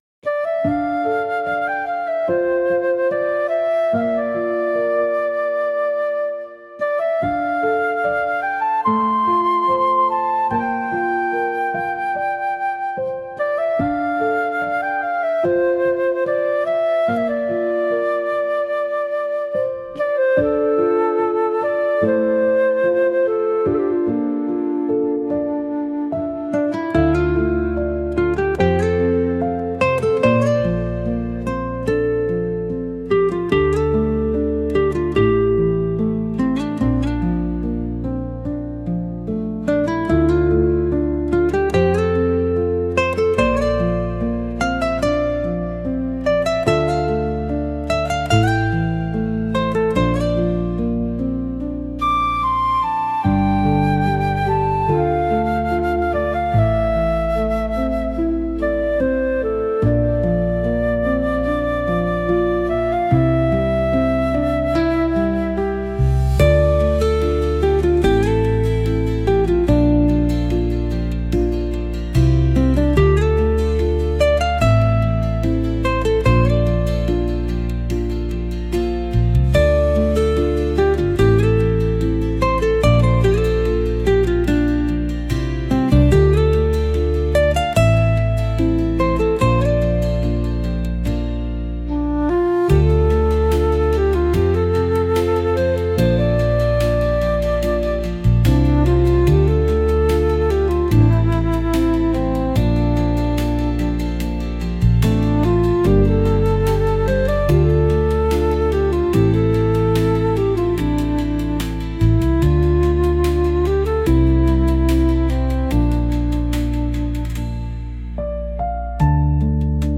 過去の記憶を思い出すようなしっとりとしたケルト音楽です。